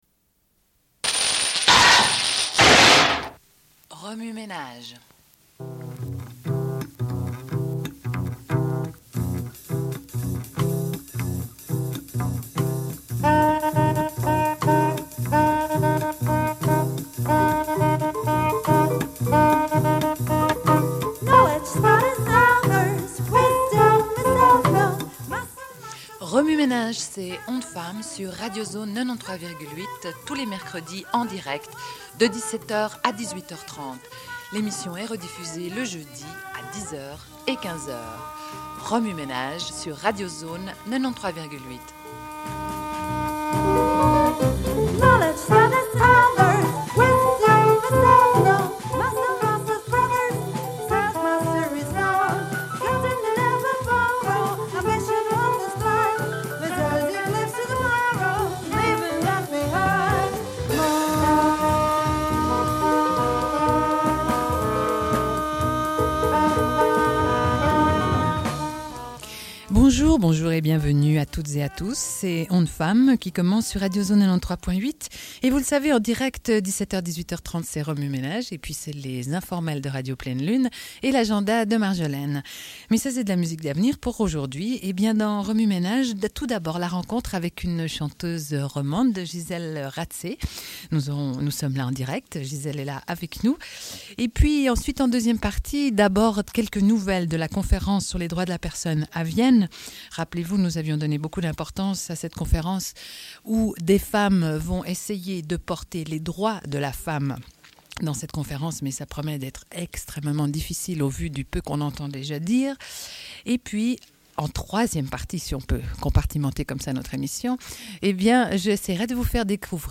Une cassette audio, face A31:18